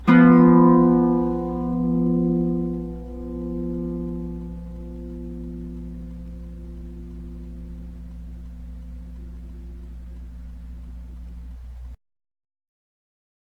• The Bajo Sexto is a twelve-string guitar.
A 7th chord